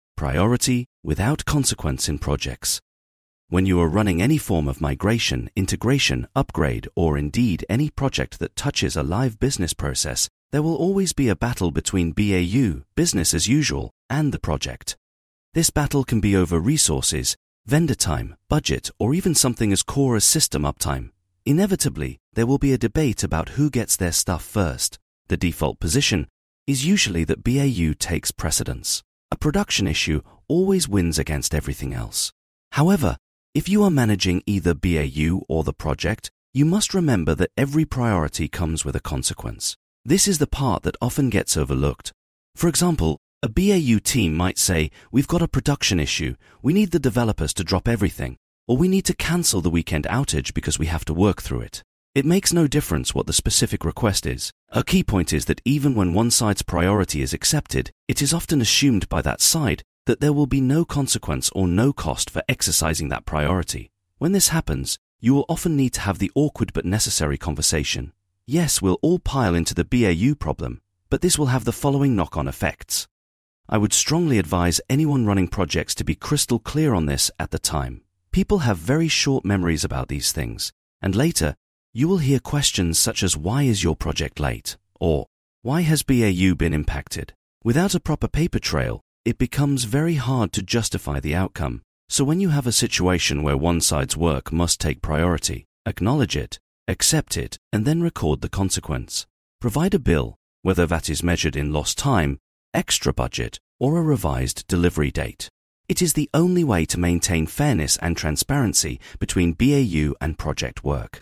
I use one both to ensure all my blog posts have an audio version and because, as someone who is badly dyslexic, I find it the best way of proofreading my work.